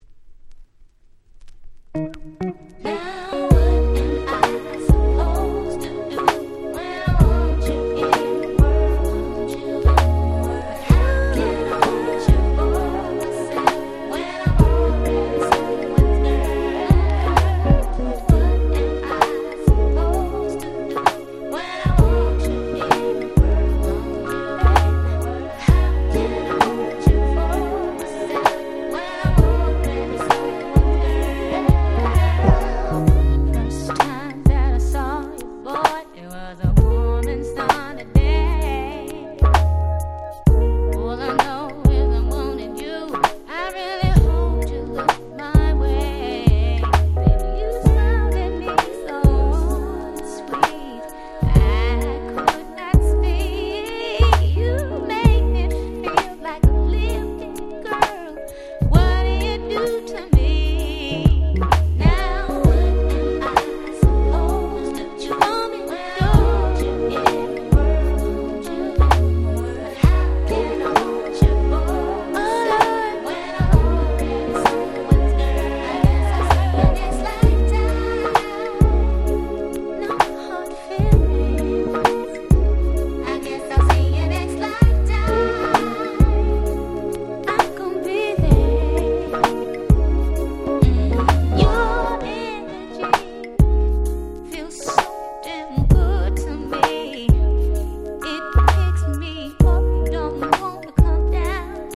97' Very Nice R&B / Neo Soul !!
ネオソウル